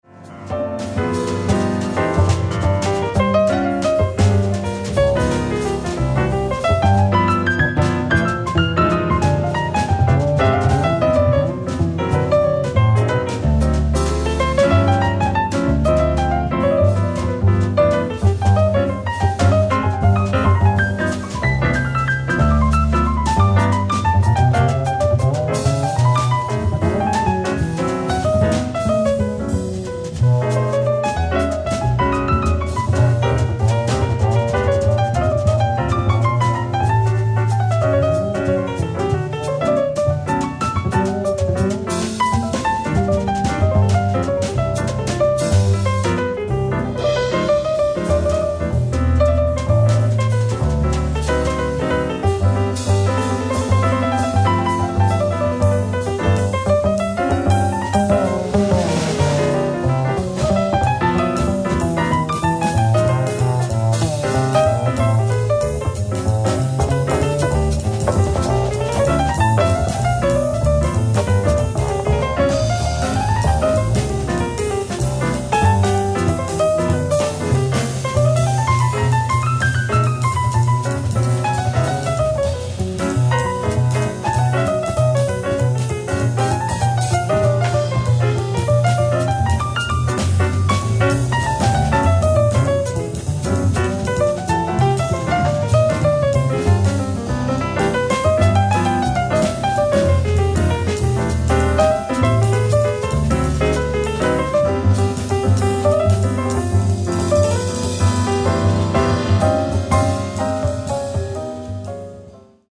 ライブ・アット・スタジオ １０４，メゾン・ドゥ・ラジオ、パリ、フランス 11/05/1976
※試聴用に実際より音質を落としています。